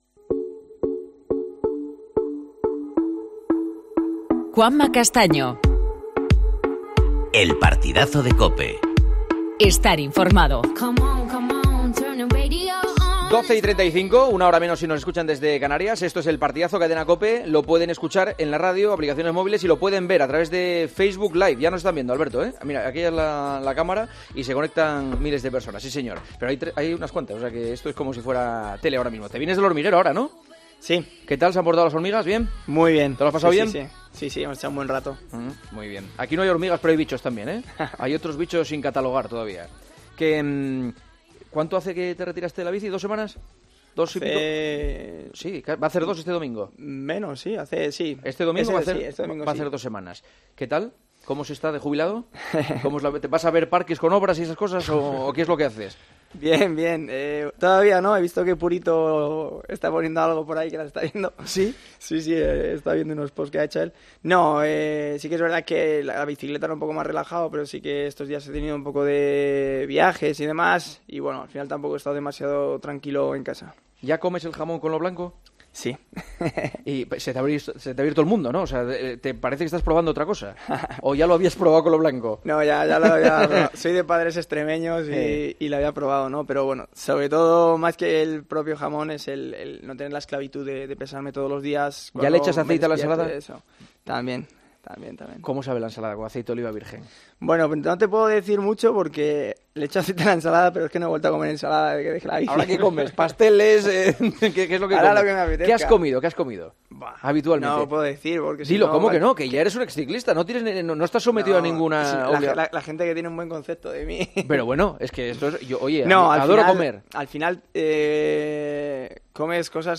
Entrevista a Alberto Contador, en El Partidazo de COPE
AUDIO: El exciclista español visita los estudios de El Partidazo de COPE.